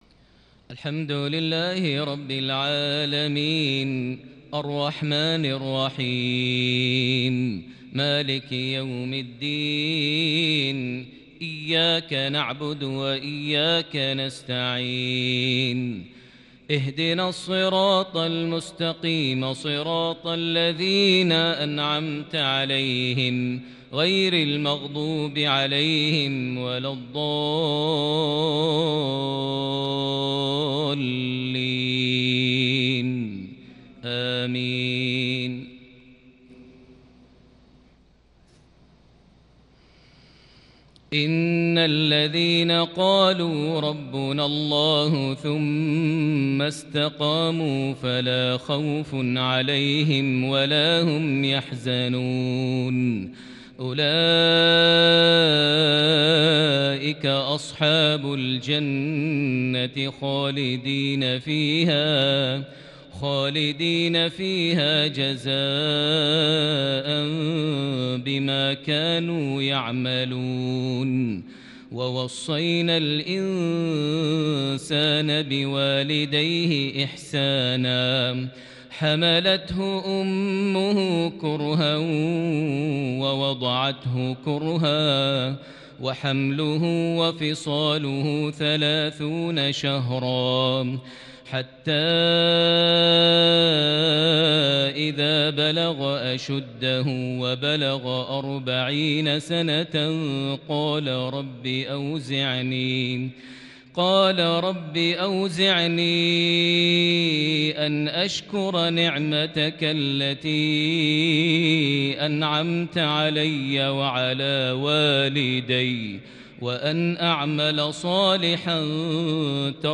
تلاوة كردية من سورة الأحقاف ( 13-19) مغرب الأربعاء 17 ذو القعدة 1441هـ > 1441 هـ > الفروض - تلاوات ماهر المعيقلي